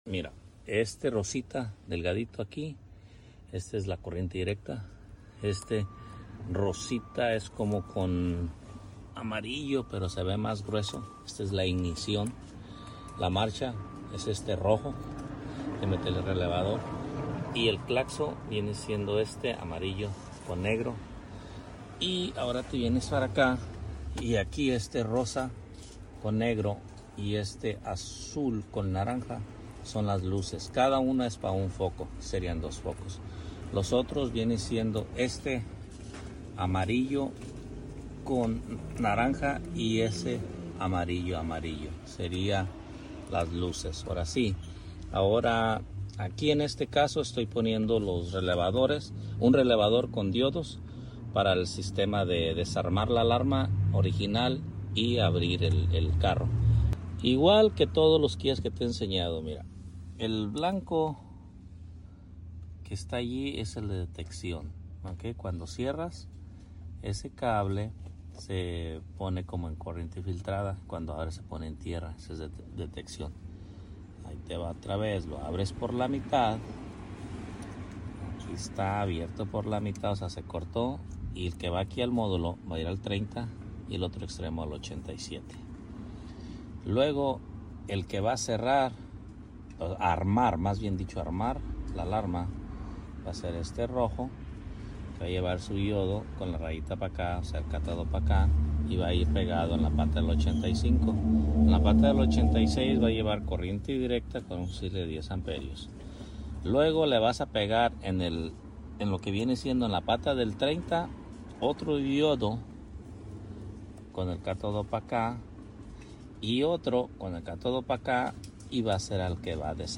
desactivando Alarma original 2018 Kia Sportage